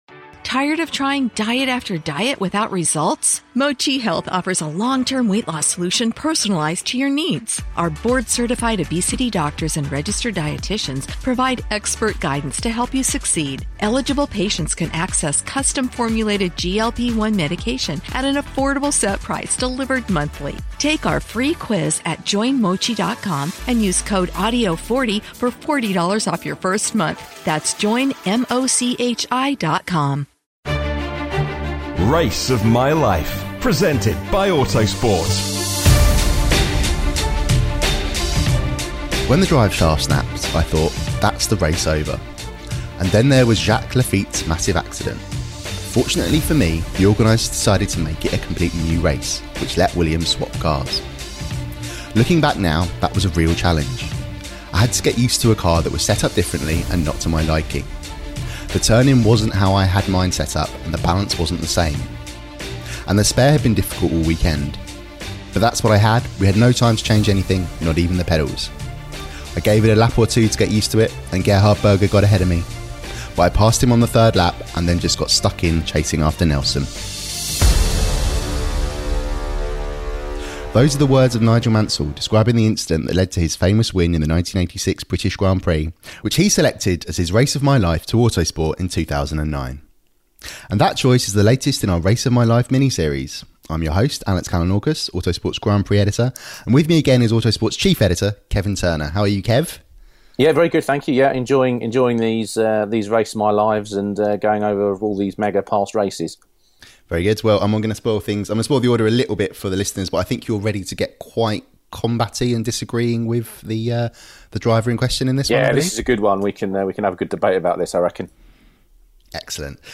Few drivers experience the thrill of winning their home grand prix; fewer still after their driveshaft snaps during the race. Nigel Mansell tells the story of the race of his life.